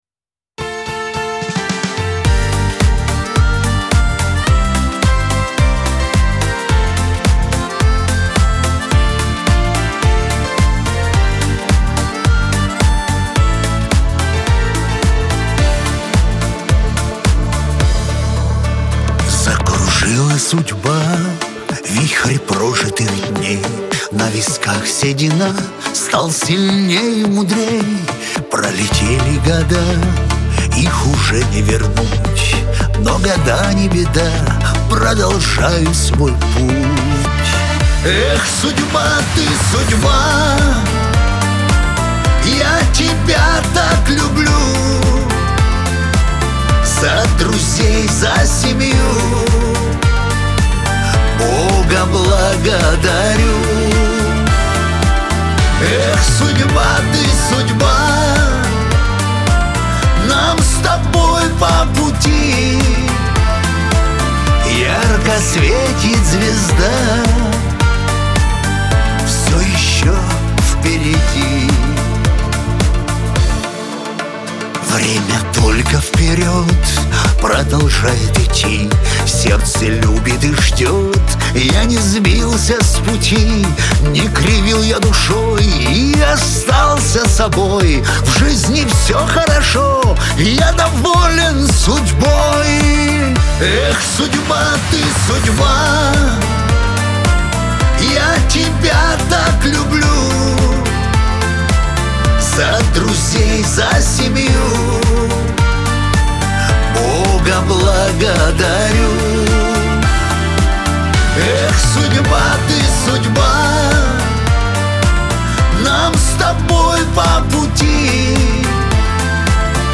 эстрада
диско
pop